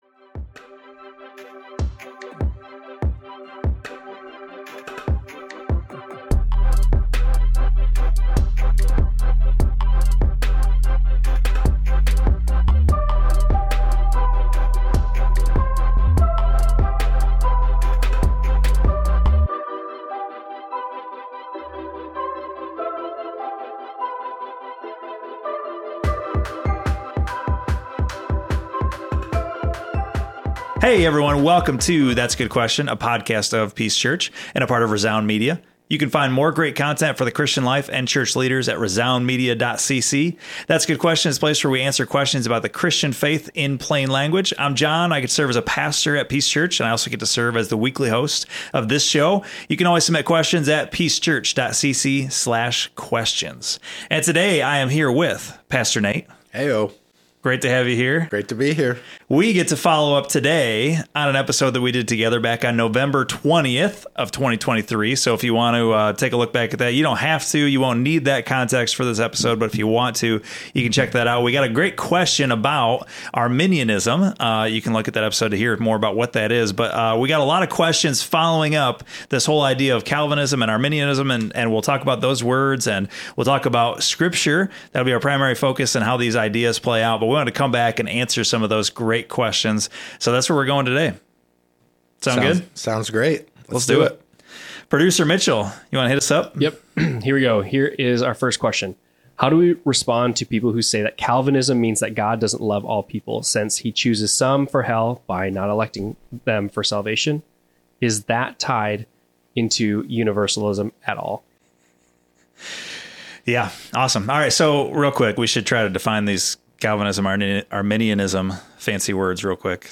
Listen in as they unpack profound theological concepts in a conversation filled with hope and love.